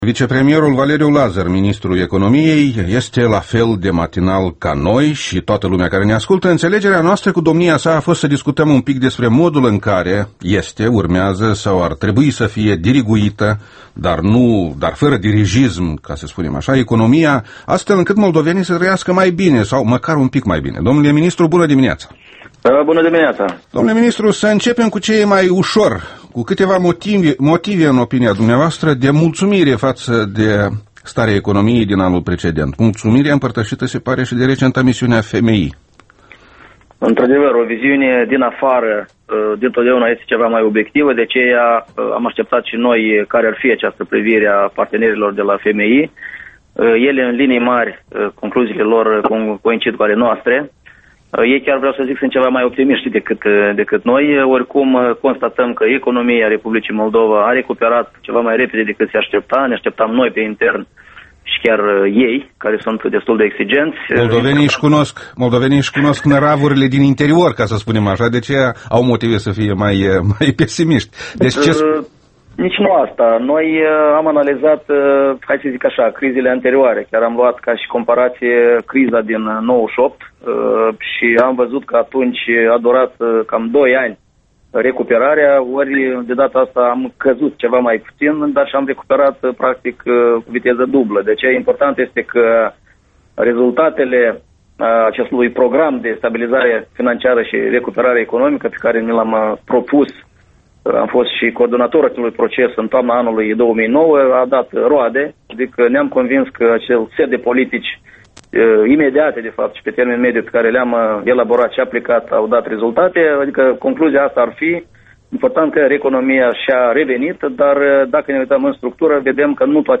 Interviul matinal EL: cu Valeriu Lazăr